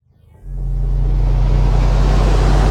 wind_up_exo.ogg